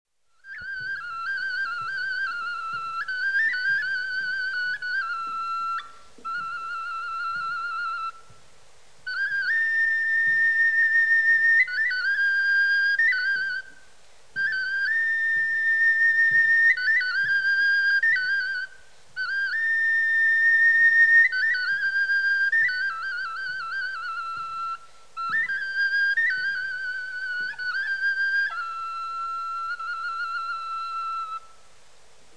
Instruments traditionnels arméniens
Le shvi, sorte de piccolo, est une flûte à bec, avec une bague métallique réglant la tonalité. Il a un timbre très aigu.
C'est un instrument soliste qui apporte beaucoup de coloration aux mélodies rapides, grâce aussi à la souplesse de son jeu.